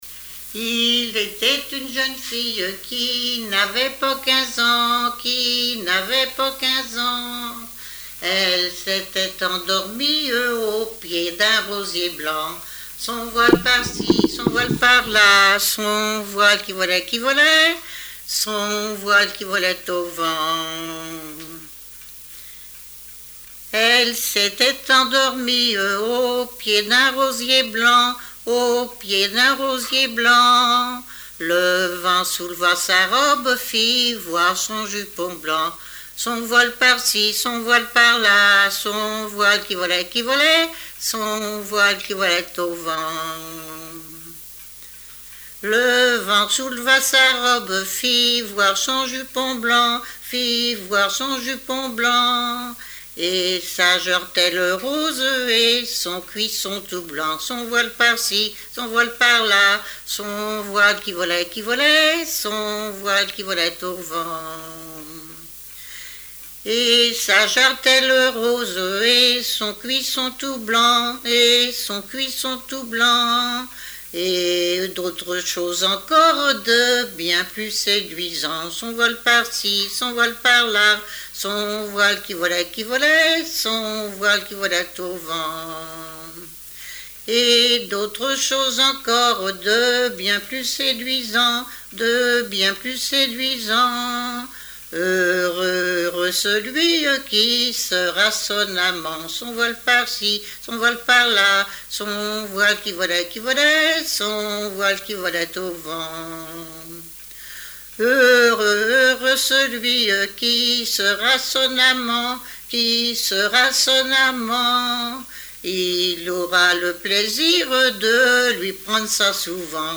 Fonction d'après l'analyste gestuel : à marcher
Genre laisse
Répertoire de chansons populaires et traditionnelles
Pièce musicale inédite